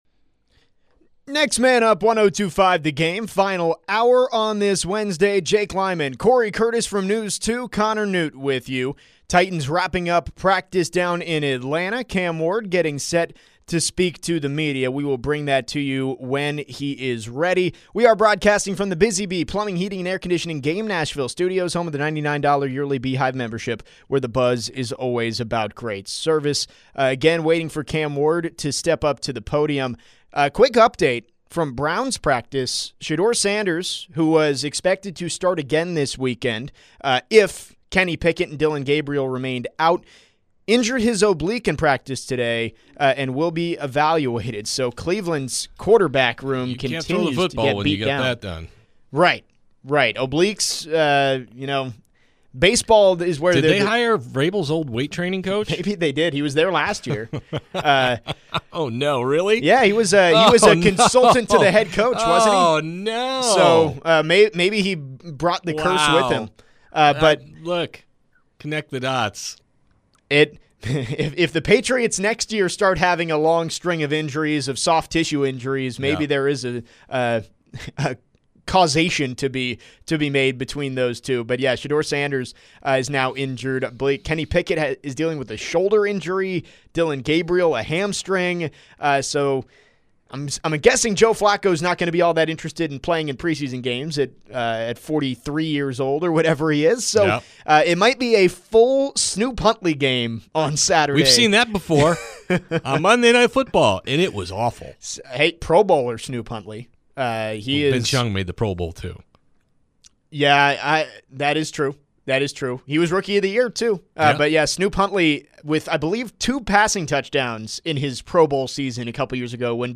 and QB Cam Ward speaks to the media